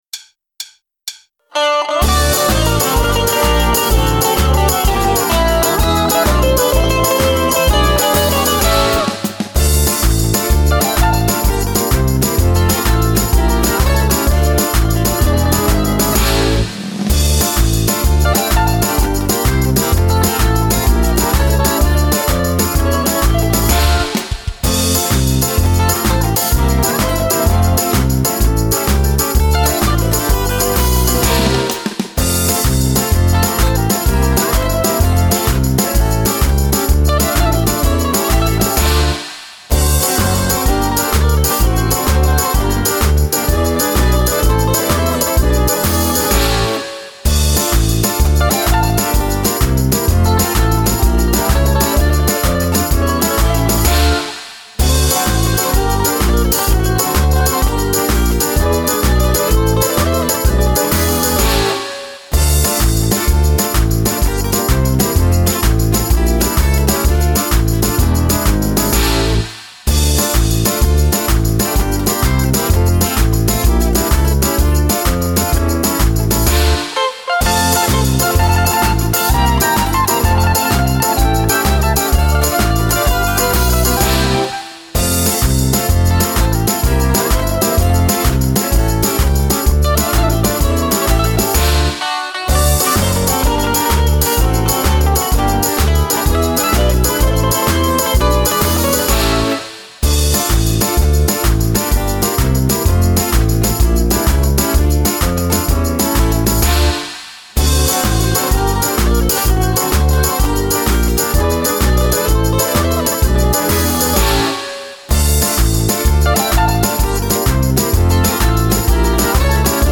Le play back
rythmique